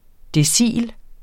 decil substantiv, fælleskøn Bøjning -en, -er, -erne Udtale [ deˈsiˀl ] Oprindelse afledt af latin decem 'ti' Betydninger 1.